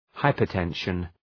Προφορά
{,haıpər’tenʃən}
hypertension.mp3